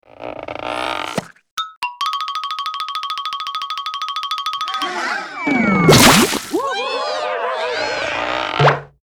audio: Converted sound effects